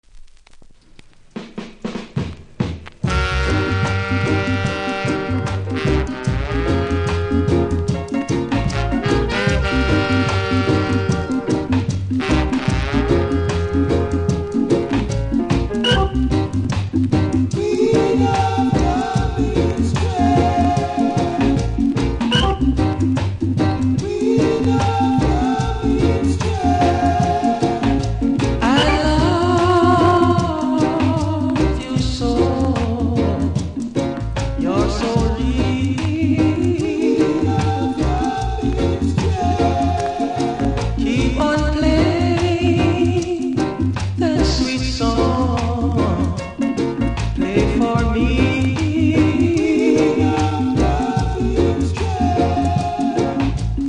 キズもノイズも少なめなので試聴で確認下さい。